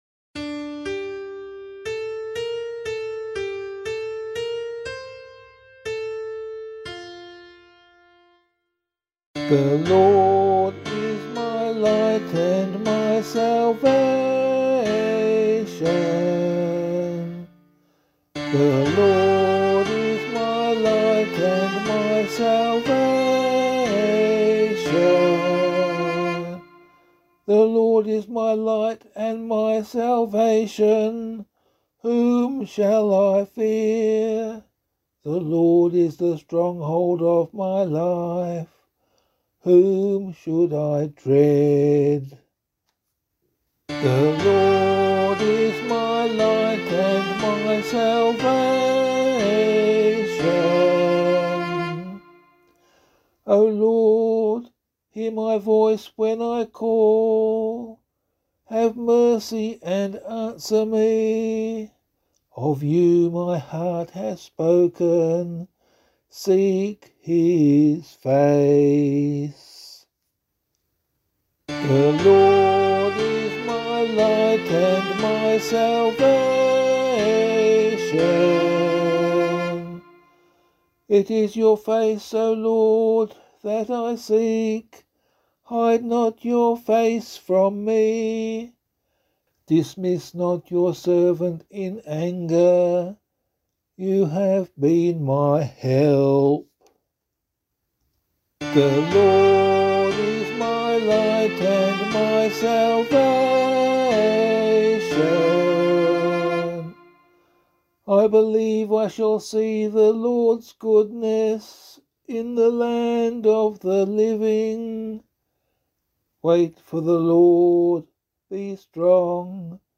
014 Lent 2 Psalm C [APC - LiturgyShare + Meinrad 3] - vocal.mp3